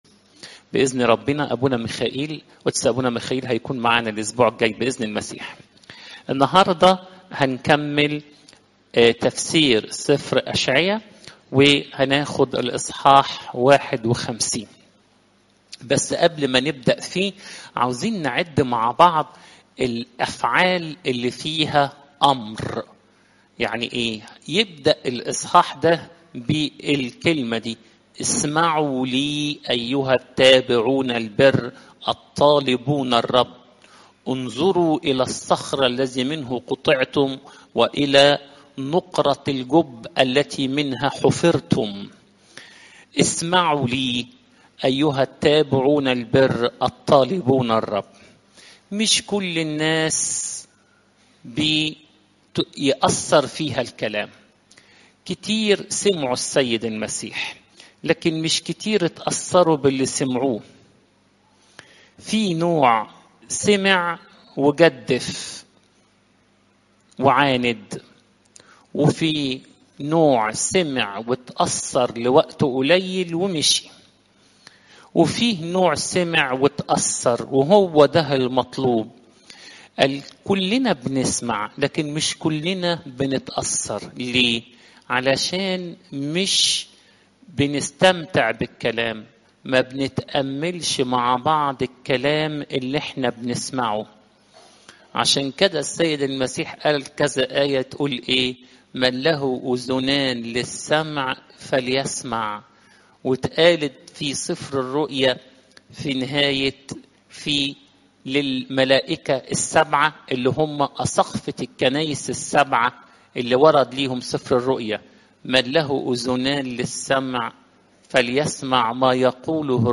إجتماع الملاك ميخائيل